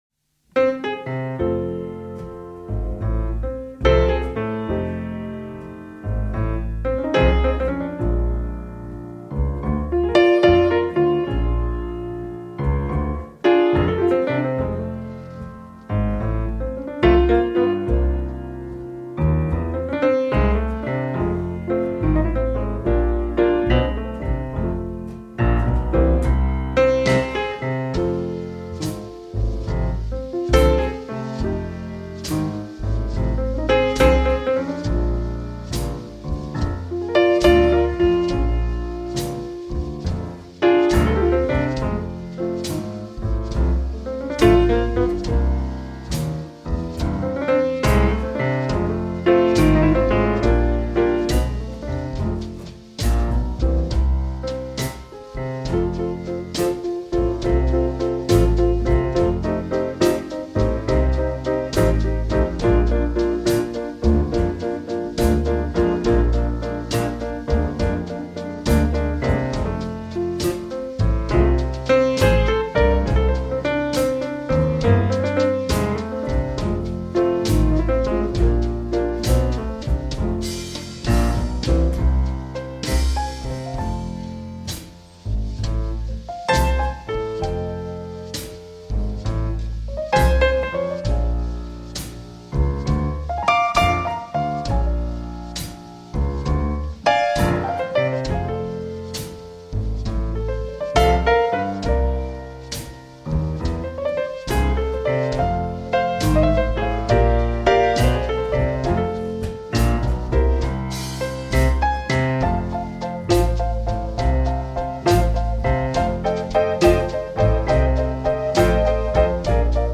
• Includes pure unadulterated boogie woogie and blues.
• I play on a Steinway and Sons "B" grand piano
p/d/b